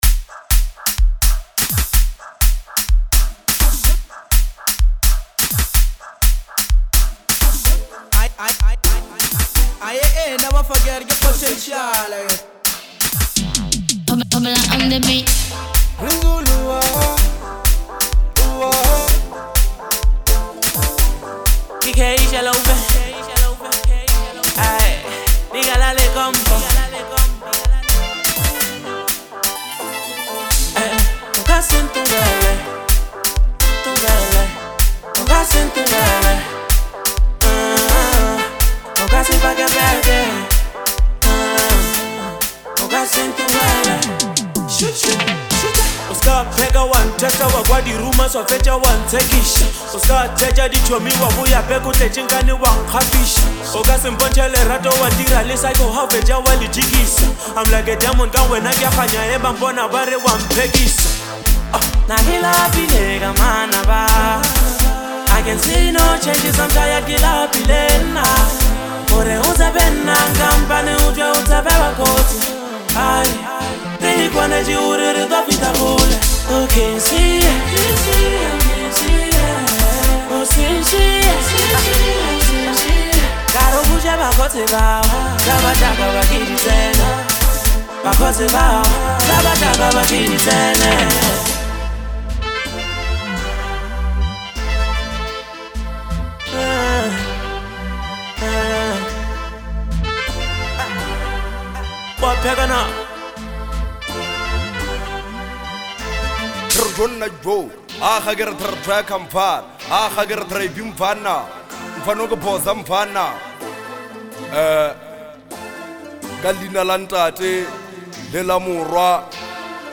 heartfelt Afrohouse track